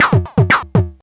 SYNTH_1_L.wav